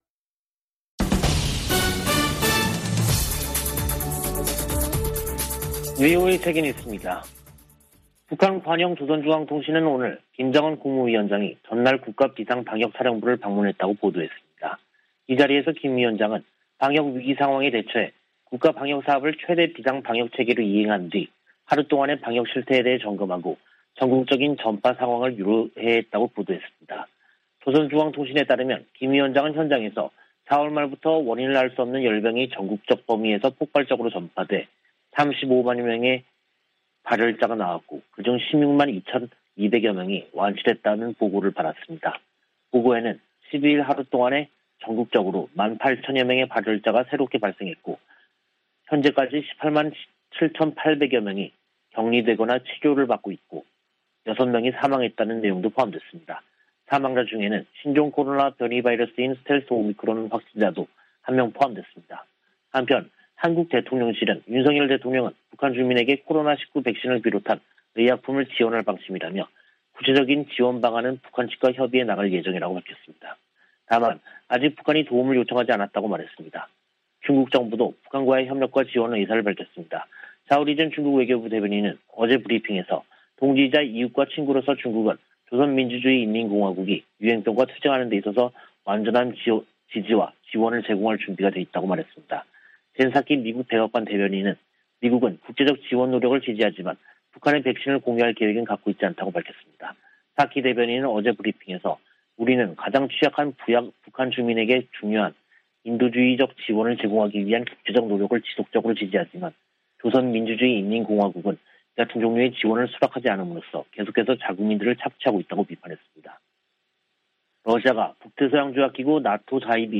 VOA 한국어 간판 뉴스 프로그램 '뉴스 투데이', 2022년 5월 13일 3부 방송입니다. 백악관은 북한이 이달 중 핵실험 준비를 끝낼 것으로 분석했습니다.